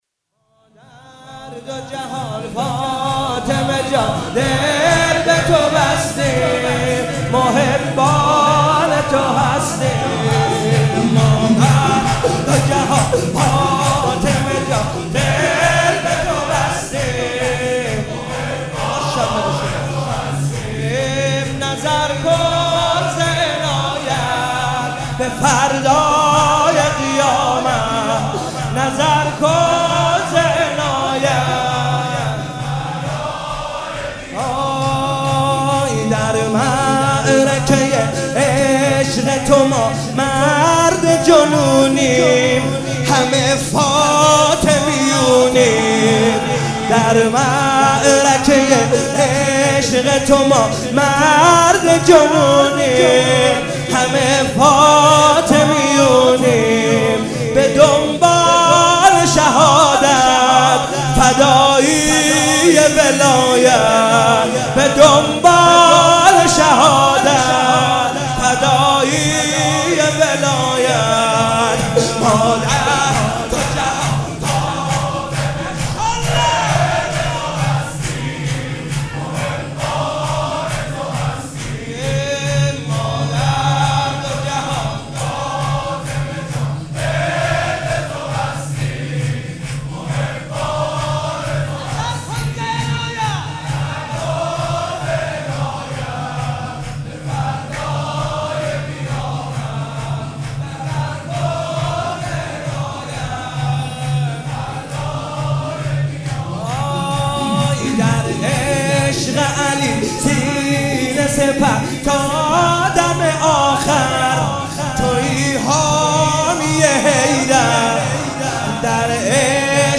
مناسبت : شهادت حضرت فاطمه زهرا سلام‌الله‌علیها
مداح : کربلایی حسین طاهری قالب : شور